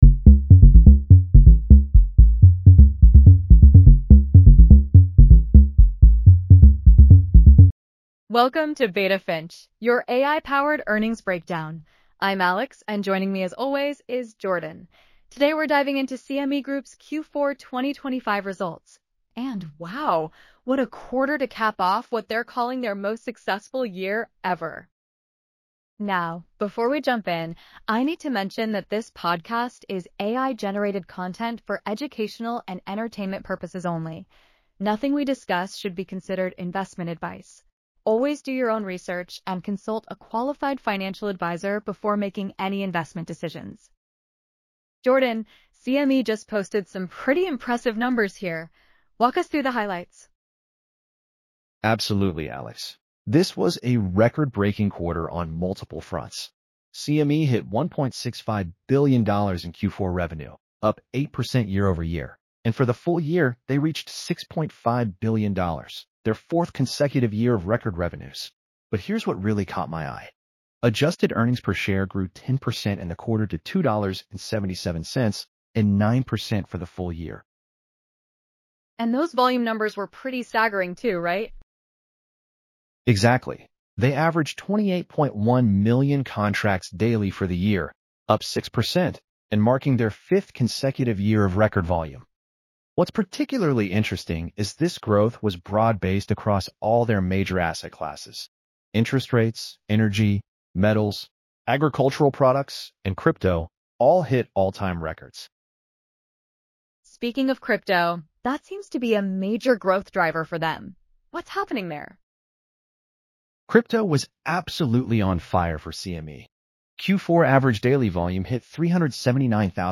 CME Q4 2025 Earnings Analysis